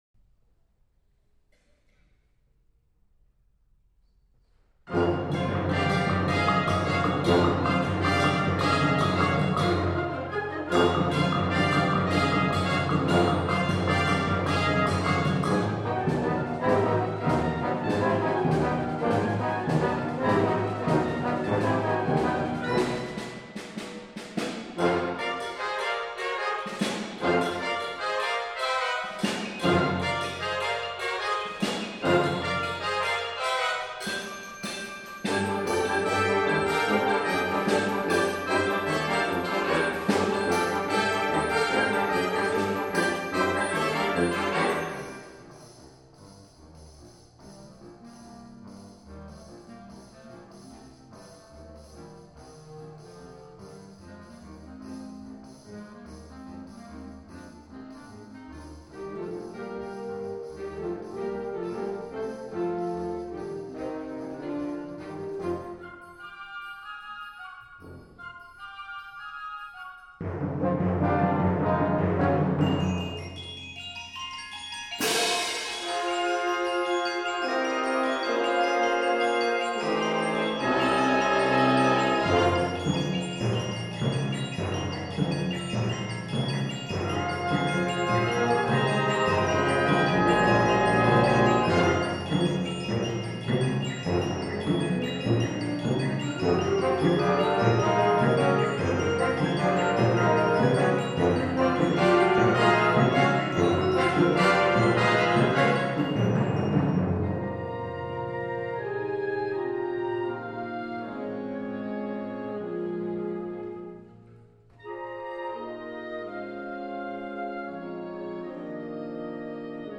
Genre: Band
Percussion 1 (xylophone, bells, vibraphone)